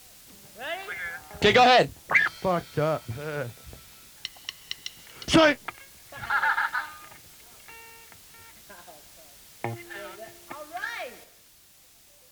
12-WD40_Banter.wav